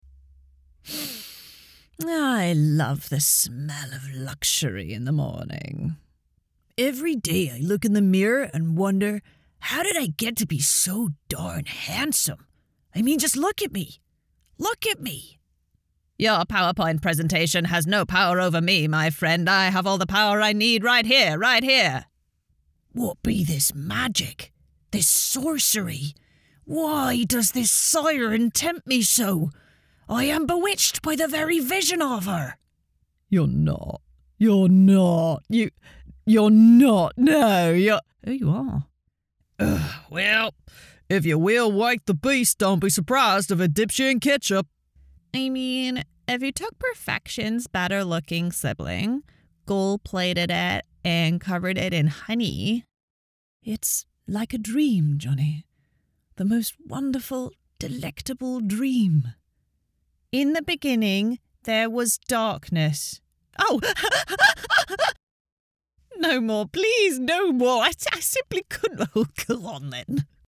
English (British)
Commercial, Natural, Accessible, Versatile, Warm